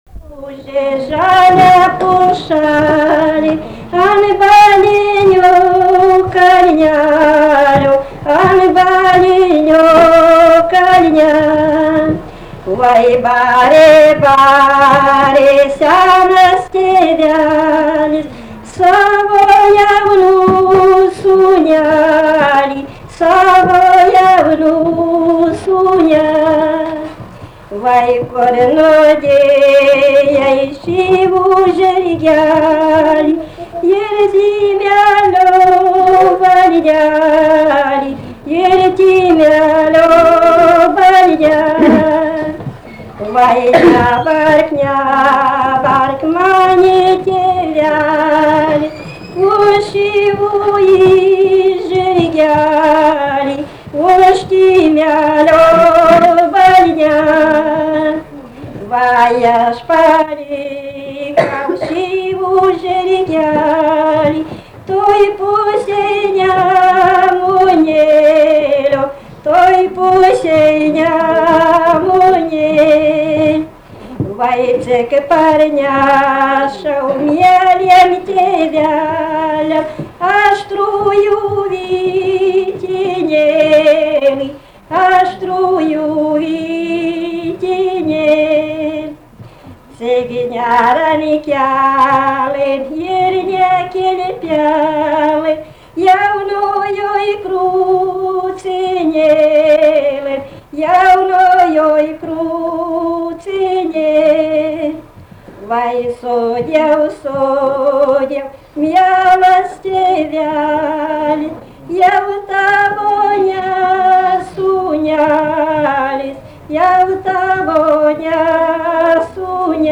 Tipas daina
Atlikimo pubūdis vokalinis